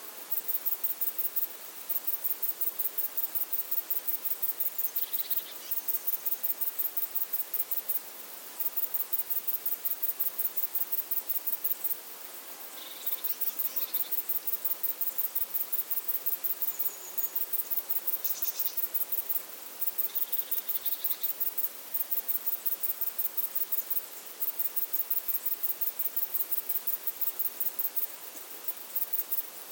crickets_7.ogg